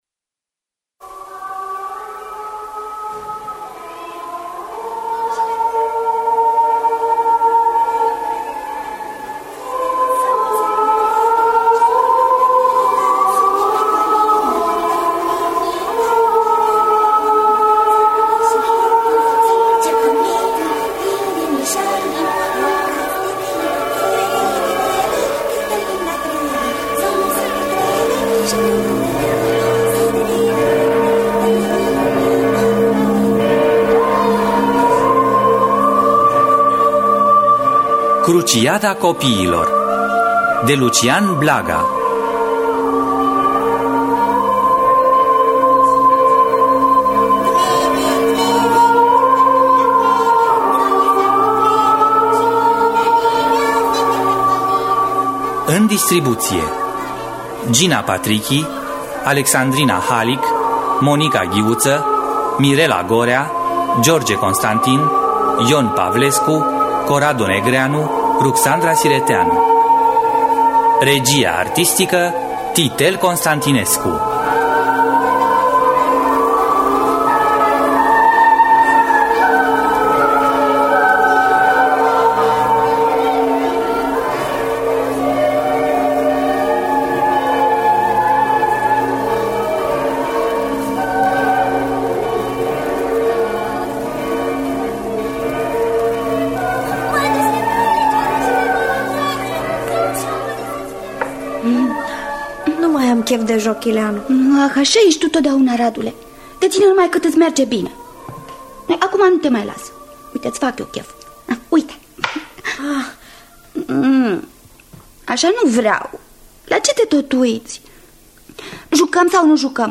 „Cruciada copiilor” de Lucian Blaga – Teatru Radiofonic Online